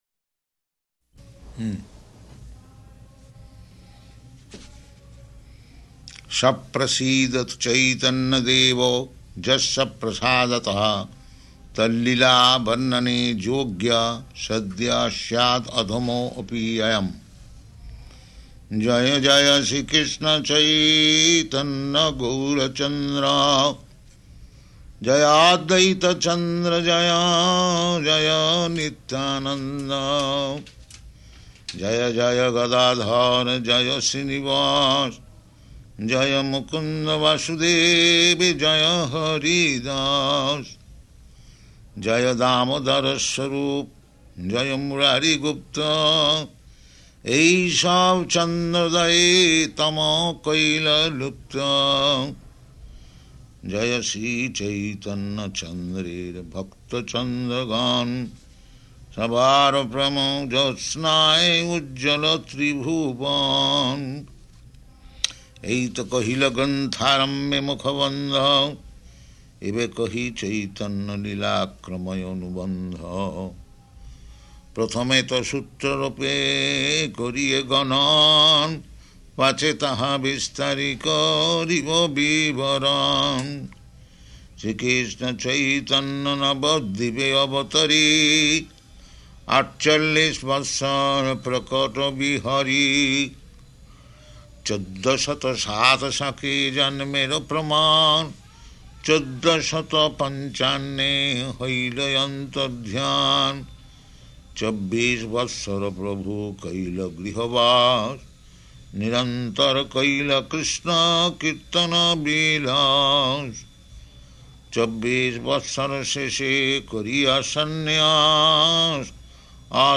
Chanting Cc Ādi-līlā 13th Chapter Verses 1–124